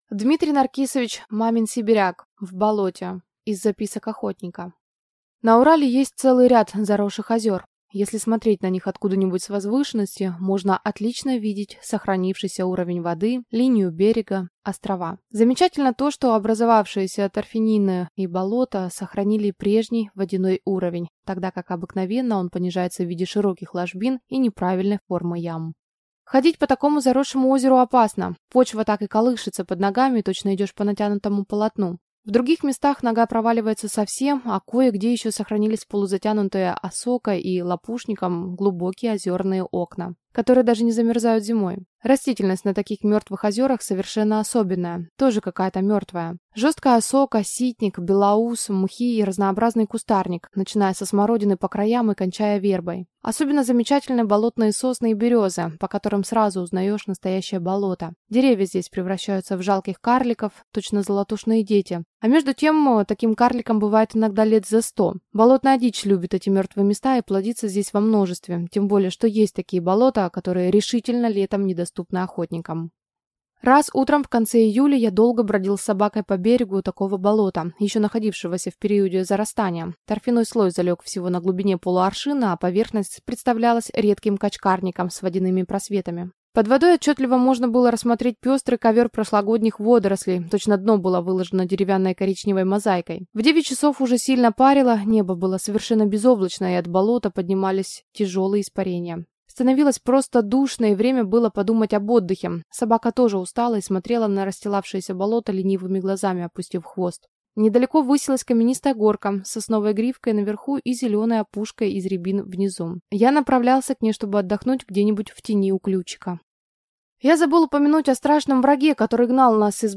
Аудиокнига В болоте | Библиотека аудиокниг